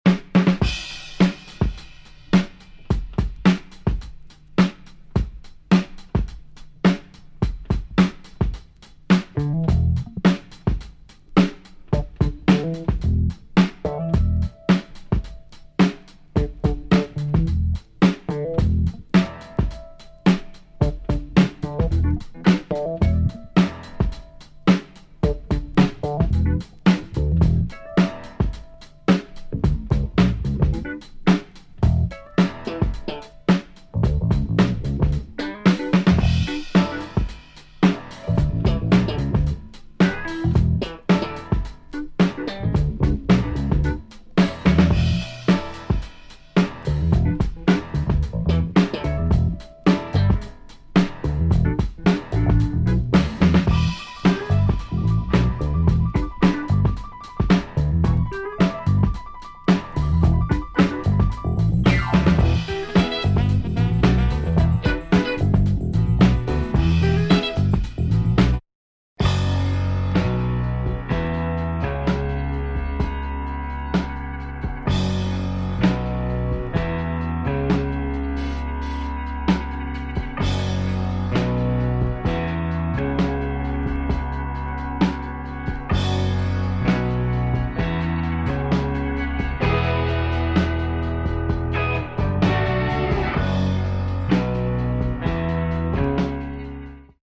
White funky soul
electric piano and phlanged drums. Fat drumbreak intro
Obscure Dutch group.